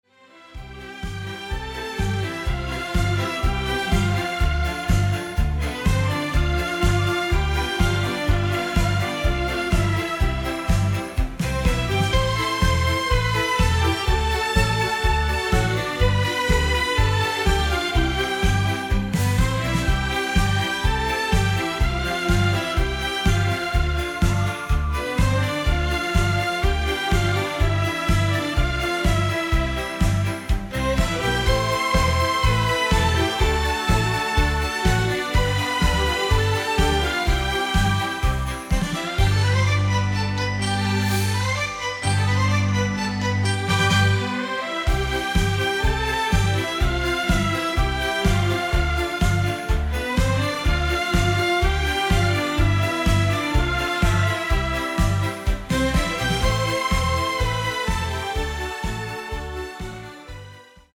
Eher zum Tanzen geeignet